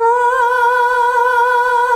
AAAAH   B.wav